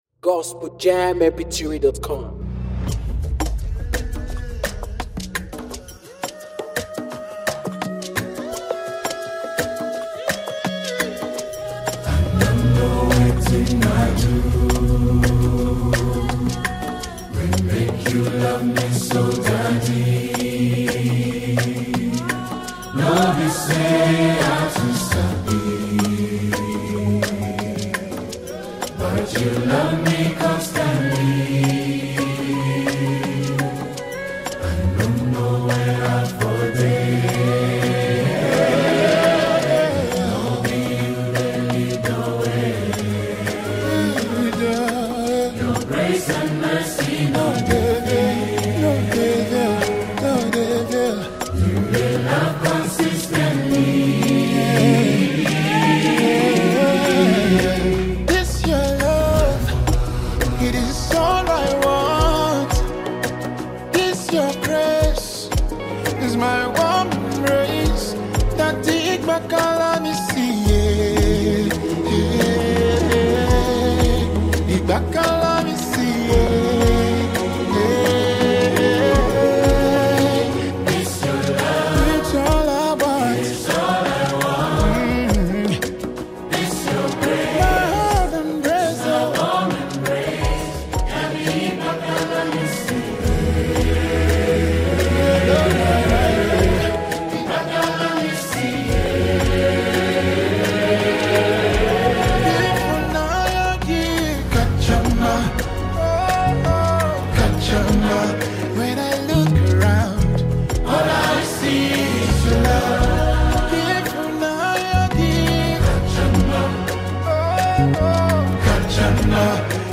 Afro beatmusic
powerful gospel anthem
heartfelt vocals
rich, soul-stirring harmonies
With uplifting melodies and spirit-filled lyrics